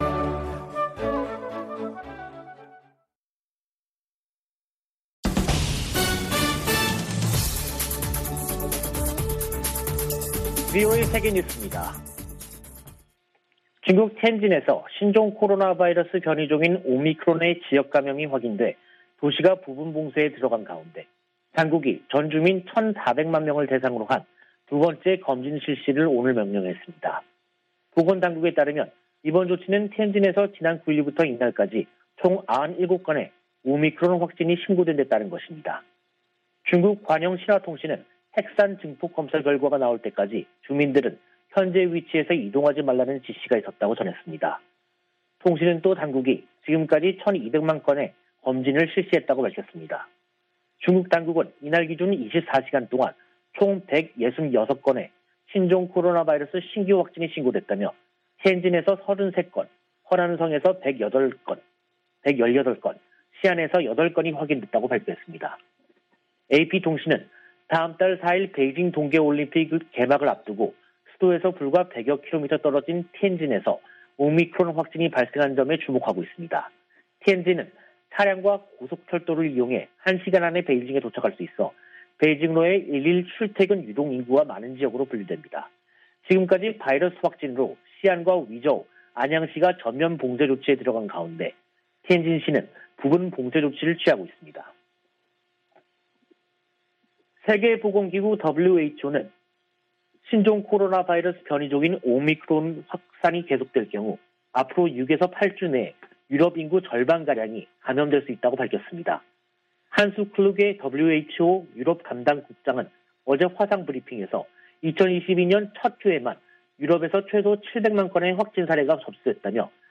VOA 한국어 간판 뉴스 프로그램 '뉴스 투데이', 2022년 1월 12일 2부 방송입니다. 북한은 11일 쏜 발사체가 극초음속 미사일이었고 최종 시험에 성공했다고 발표했습니다. 백악관이 북한의 최근 미사일 발사를 규탄하면서 추가 도발 자제와 대화를 촉구했습니다. 유엔 사무총장은 북한의 연이은 미사일을 발사를 매우 우려하고 있다고 밝혔습니다.